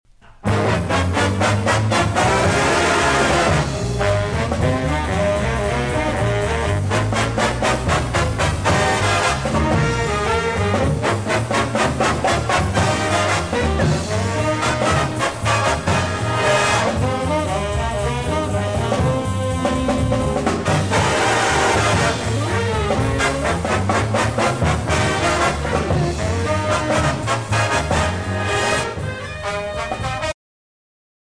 The guest musician was Lou Marini.
Jazz
trumpet
trombones
alto saxophone
tenor saxophone
baritone saxophone
piano
bass
percussion
guitar